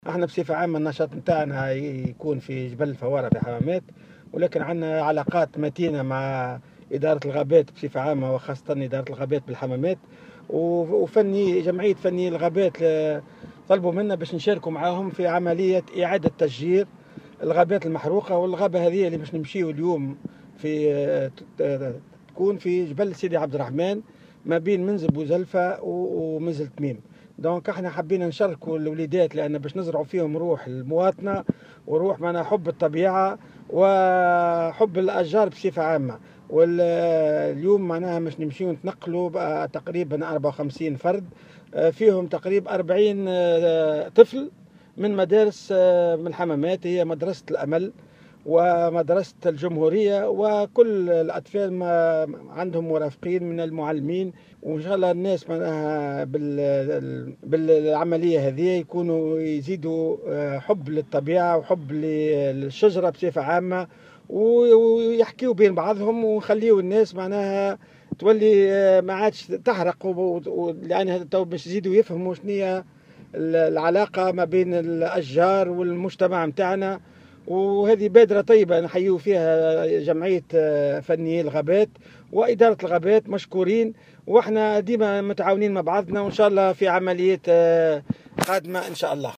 في تصريح لمراسلة الجوهرة أف أم